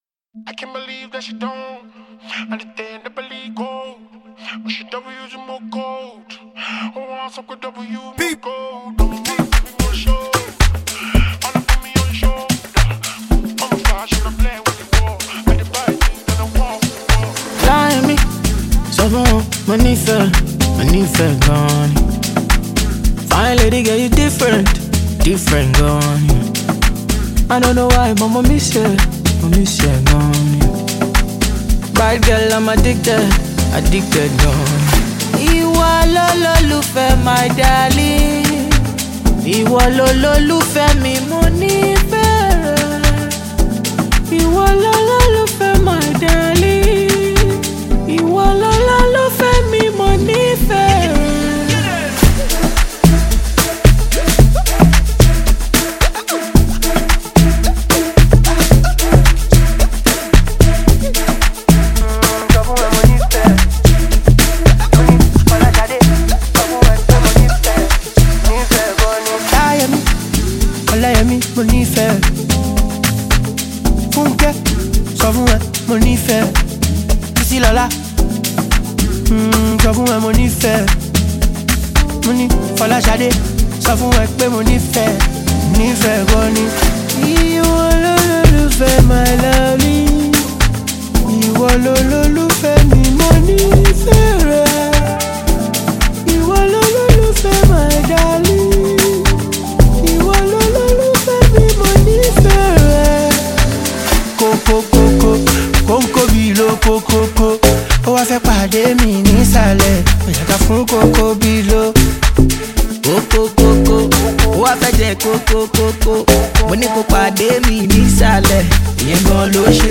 Reputable Nigerian singer
groovy new single